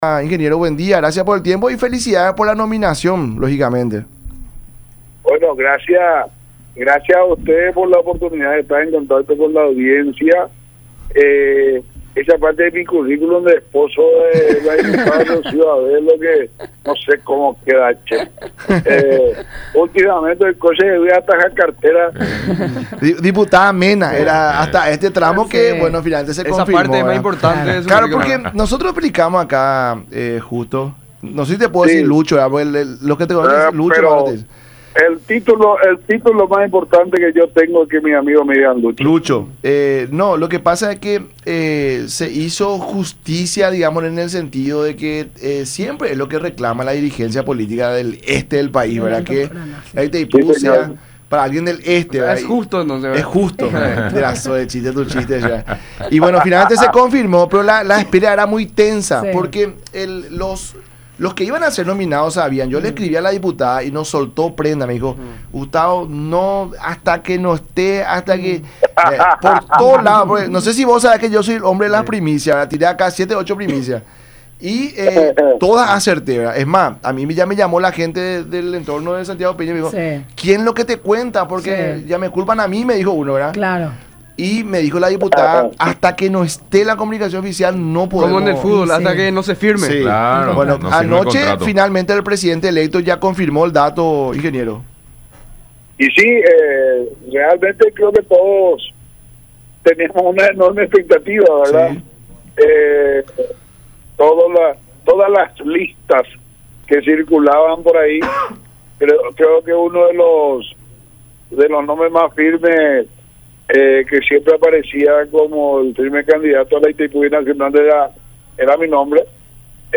“Santiago Peña, me dijo claramente que las relaciones entre Paraguay y Brasil son fundamentales, Brasil es un gran compañero de esfuerzo, esperanza y desarrollo”, mencionó en la entrevista con el programa “La Mañana De Unión” por Unión TV y radio La Unión.
05-JUSTO-ZACARIAS-IRUN.mp3